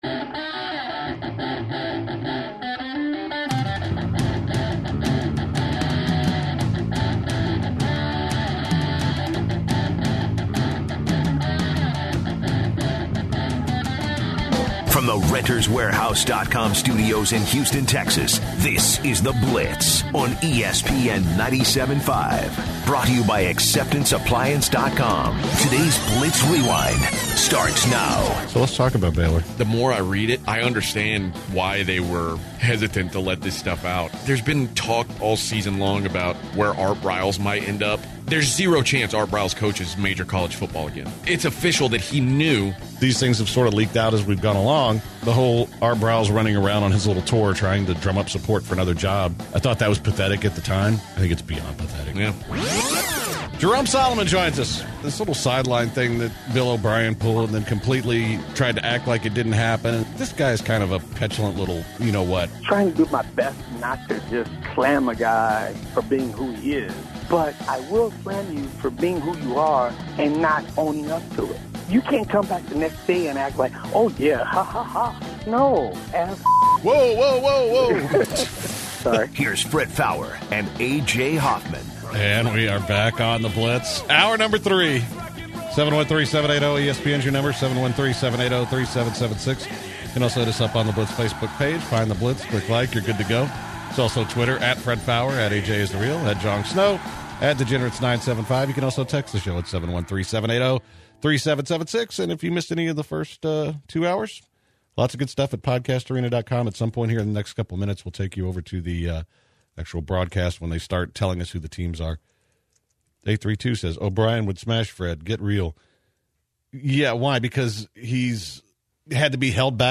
They then cut to a live broadcast where they tell us the current playoff rankings.